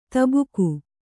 ♪ tabuku